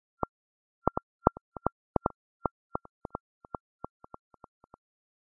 描述：第4小节有滚动帽的Getic鼓
Tag: 84 bpm Hip Hop Loops Drum Loops 3.85 MB wav Key : Unknown